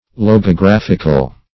Search Result for " logographical" : The Collaborative International Dictionary of English v.0.48: Logographic \Log`o*graph"ic\, Logographical \Log`o*graph"ic*al\, a. [Gr.
logographical.mp3